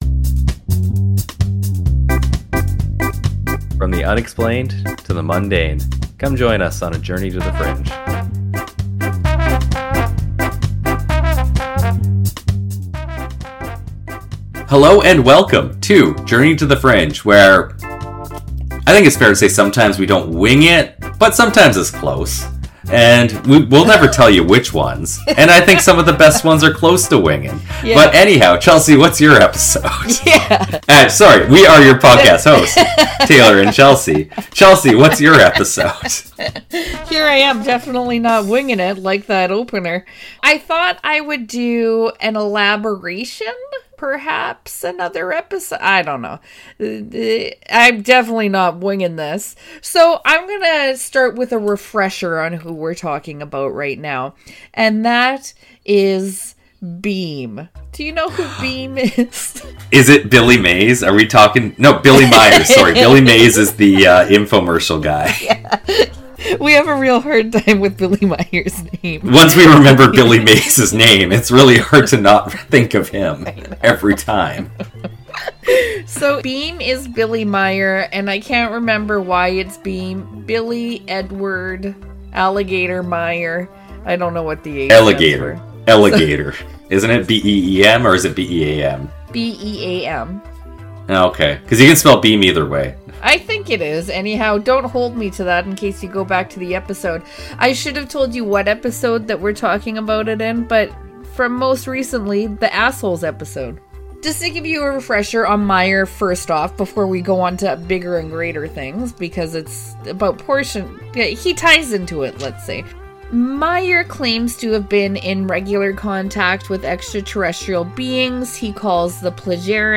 This week we're doing a 'dramatic' reading of Billy's latest contact report—an intergalactic casserole of dwarf galaxies, alien refugees, population statistics from space accountants, and Quetzal’s increasingly desperate attempts to get a word in while Billy monologues like the universe’s most self‑assured improv student.